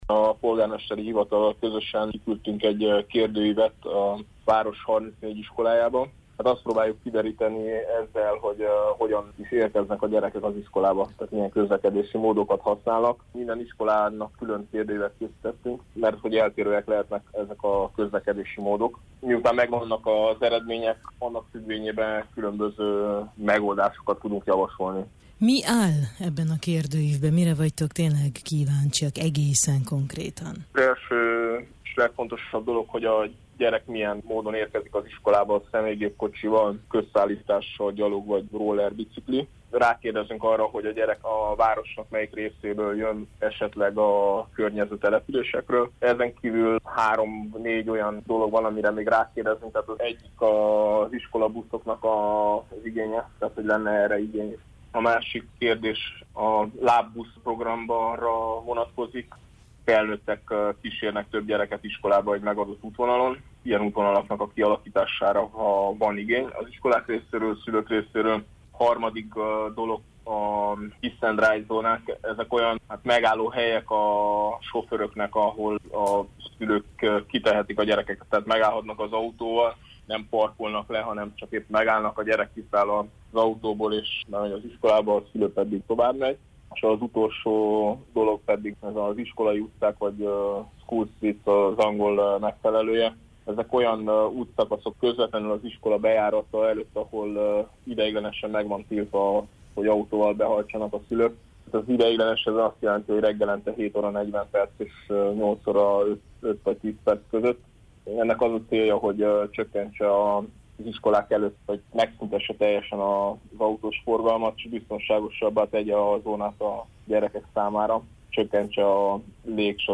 beszélgetünk